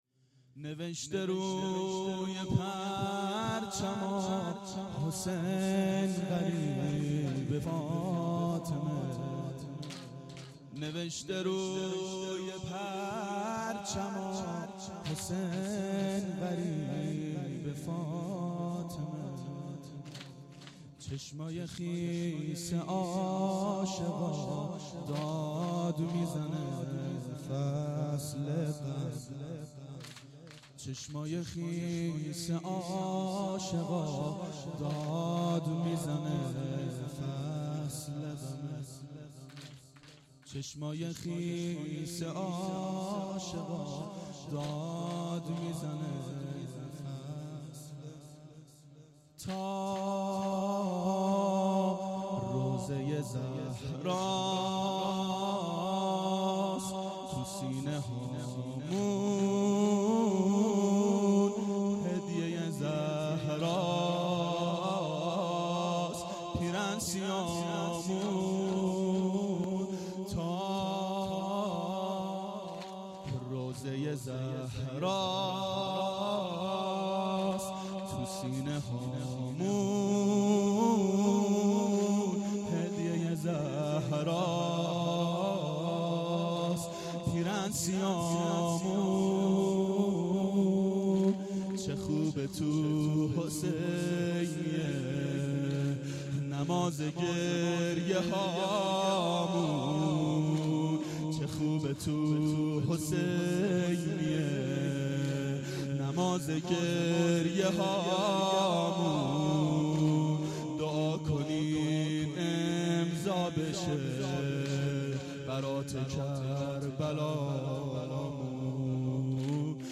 • دهه اول صفر سال 1391 هیئت شیفتگان حضرت رقیه سلام الله علیها (شب اول)